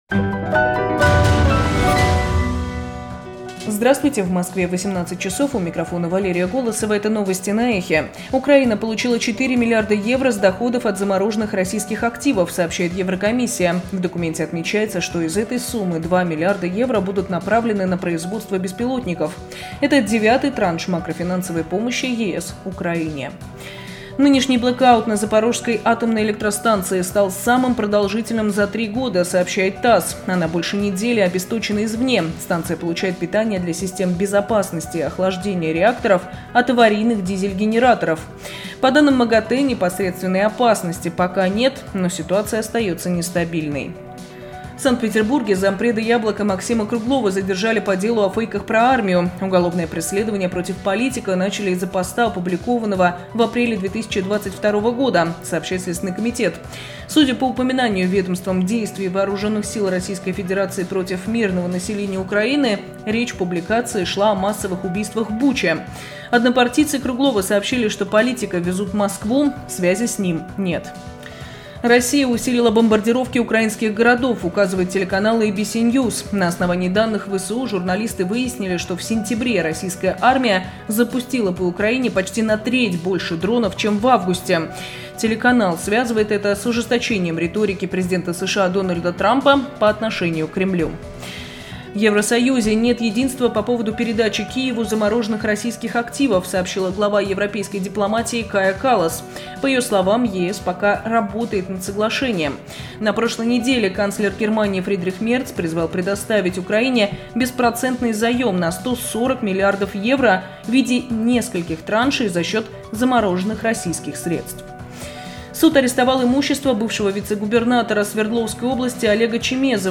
Слушайте свежий выпуск новостей «Эха»
Новости 18:00